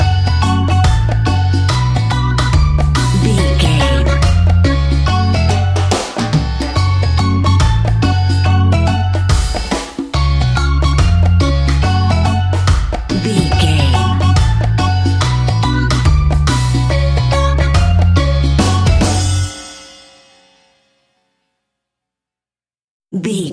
Aeolian/Minor
steelpan
drums
percussion
bass
brass
guitar